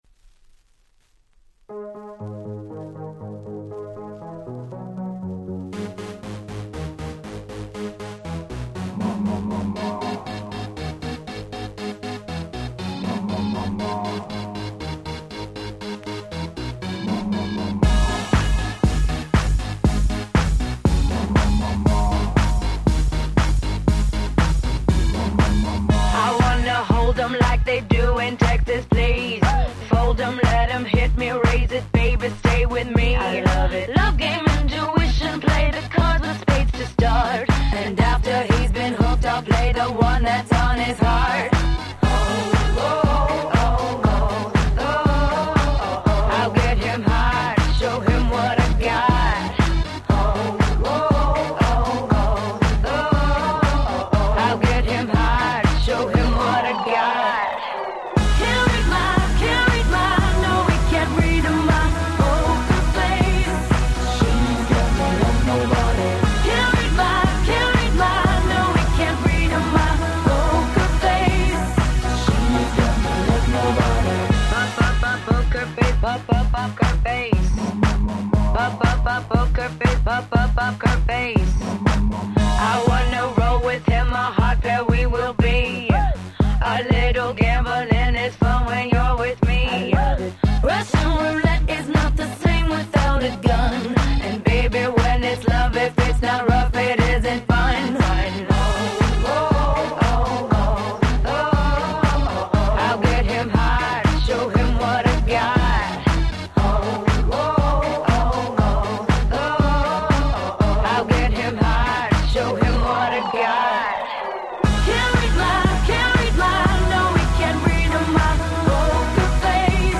09' Super Hit Pops/R&B !!